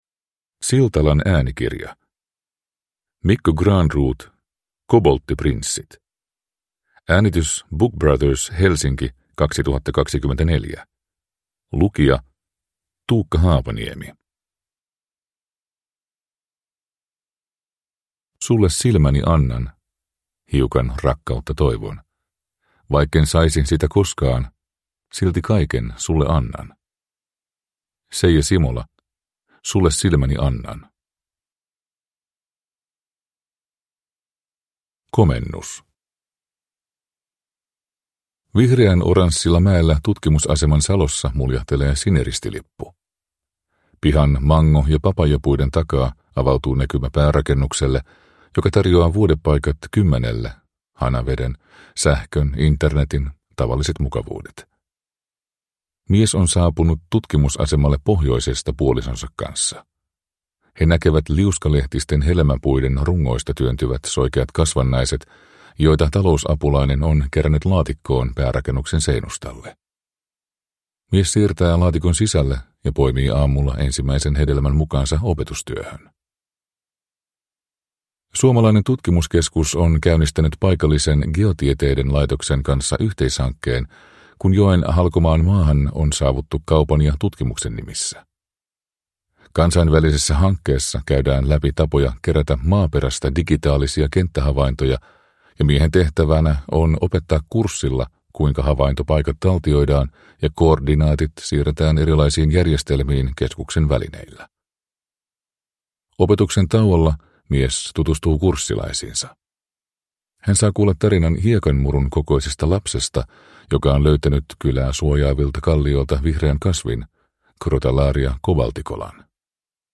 Kobolttiprinssit (ljudbok) av Mikko Granroth | Bokon